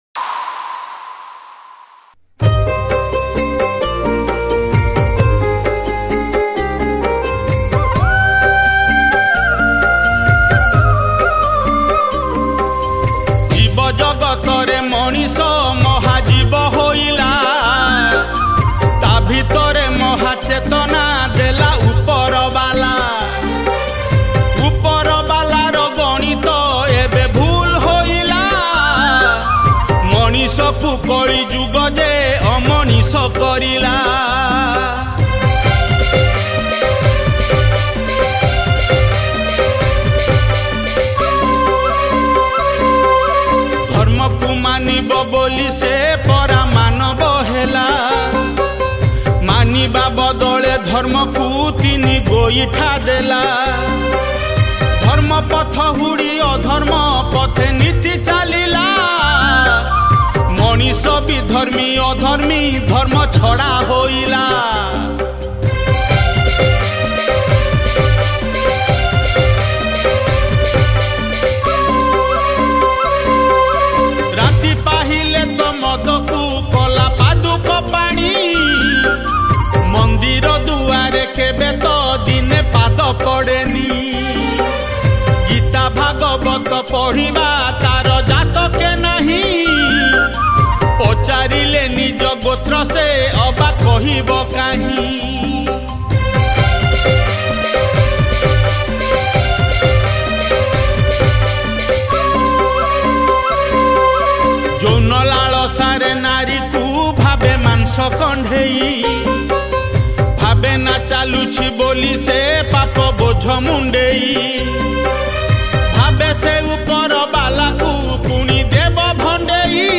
Recorded At: BM Studio